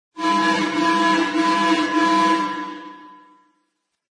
Descarga de Sonidos mp3 Gratis: sirena 2.
descargar sonido mp3 sirena 2